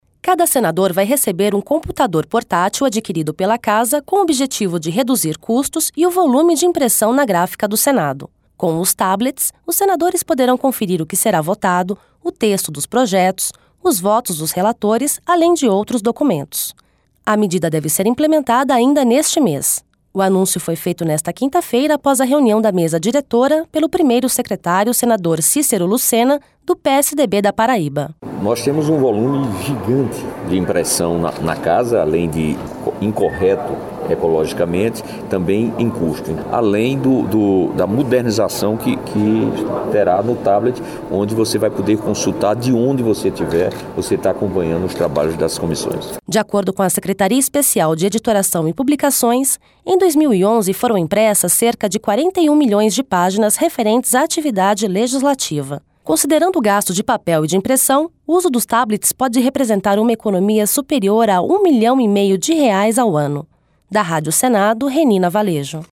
A medida deve ser implementada ainda neste mês. O anúncio foi feito nesta quinta-feira após a reunião da Mesa Diretora, pelo primeiro secretário senador Cícero Lucena, do PSDB da Paraíba.